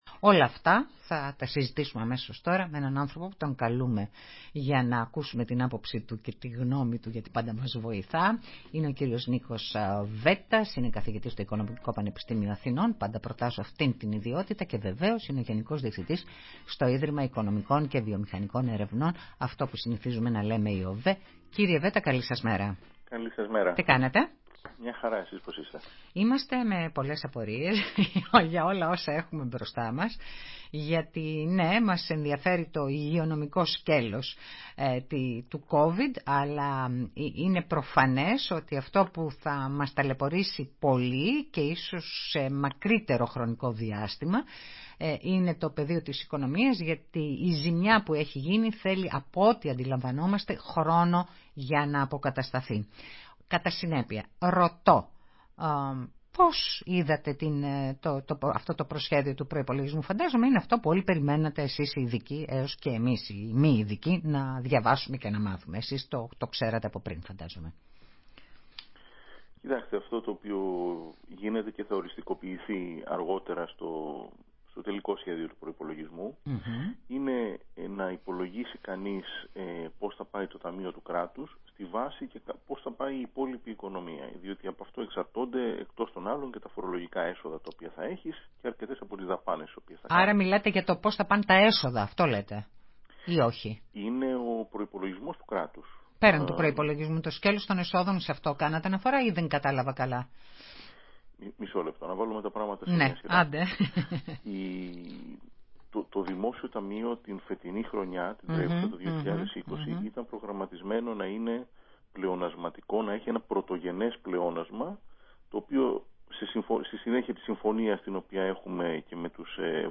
Δημοσιότητα Συνέντευξη στο ραδιόφωνο της ΕΡΤ Ημερομηνία Δημοσίευσης